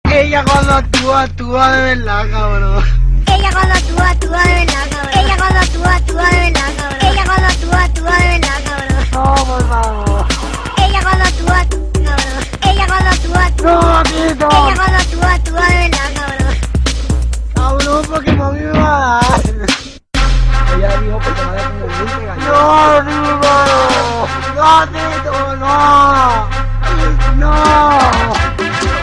vibrant melody
Ringtone